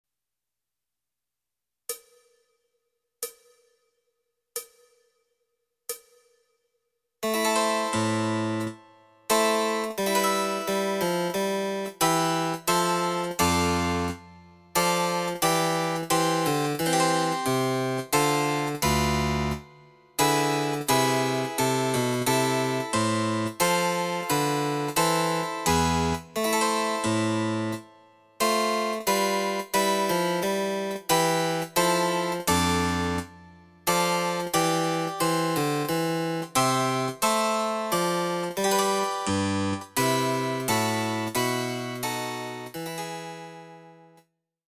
推奨テンポの伴奏
Electoric Harpsichord